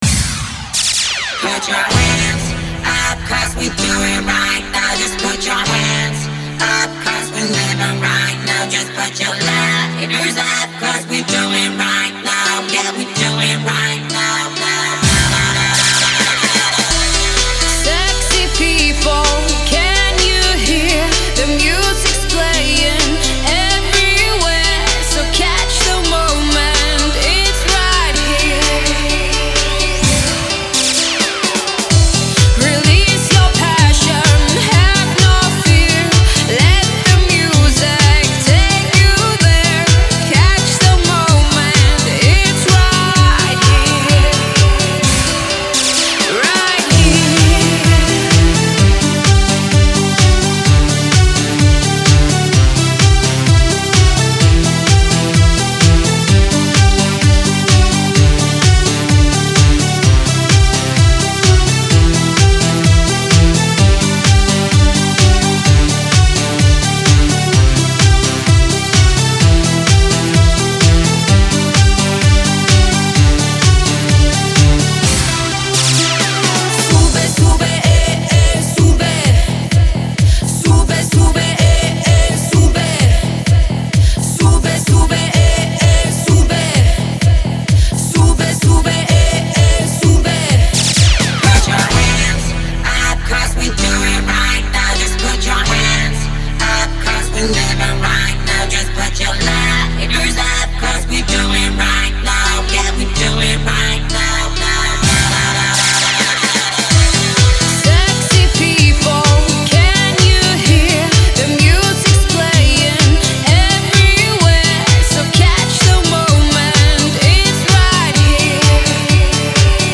Категория: Ремиксы